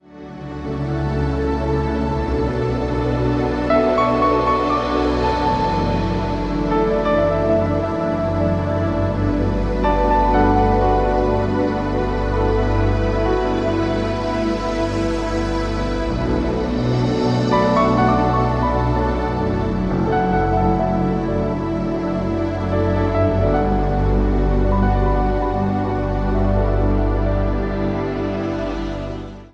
instrumental CD
birthed spontaneously during times of intimate worship